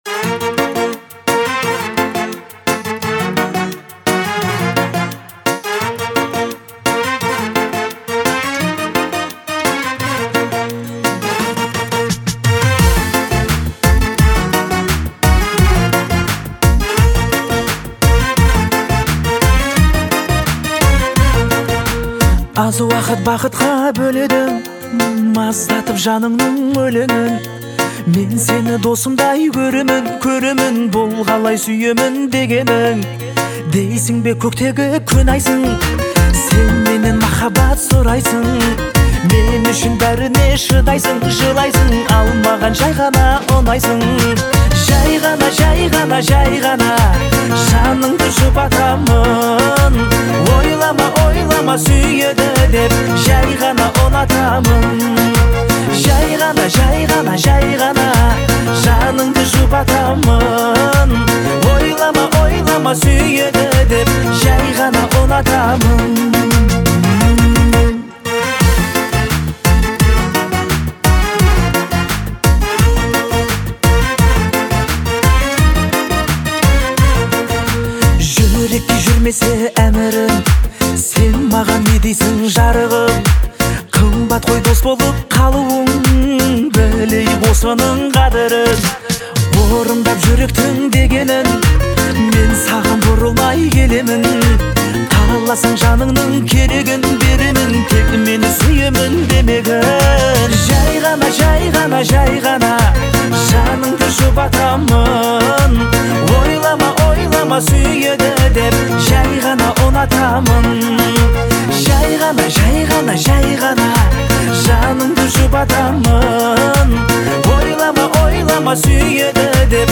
это мелодичный казахский поп-трек